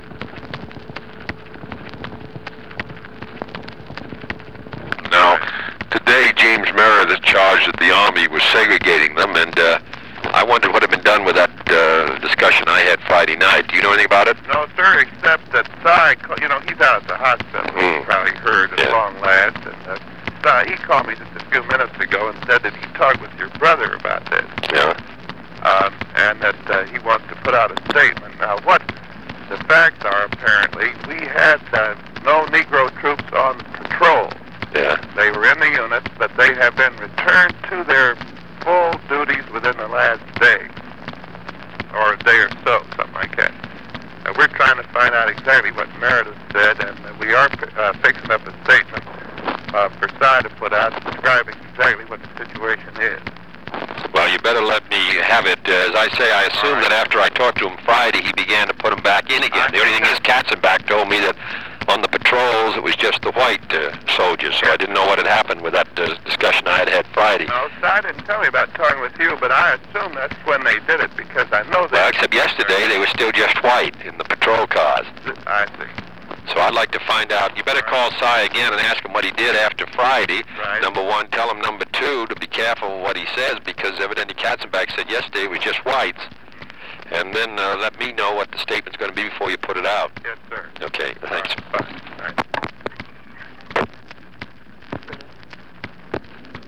Conversation with James Meredith
Secret White House Tapes | John F. Kennedy Presidency Conversation with James Meredith Rewind 10 seconds Play/Pause Fast-forward 10 seconds 0:00 Download audio Previous Meetings: Tape 121/A57.